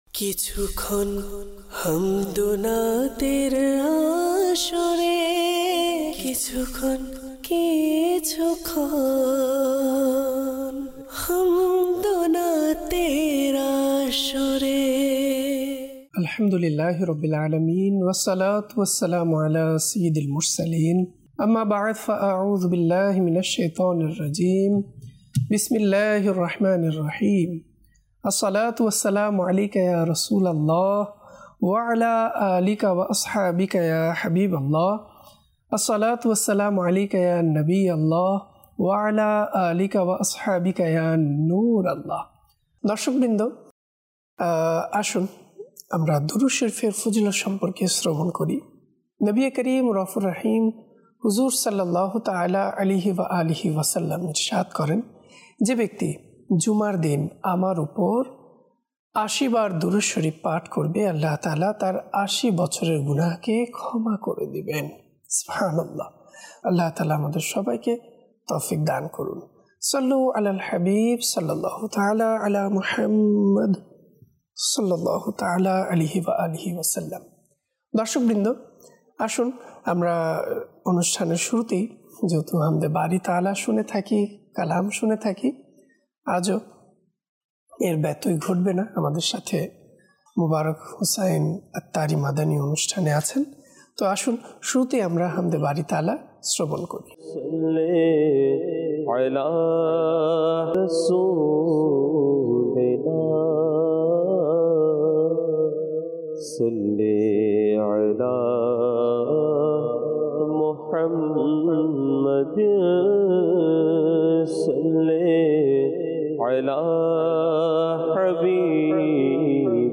কিছুক্ষণ হামদ ও নাতের আসরে EP# 347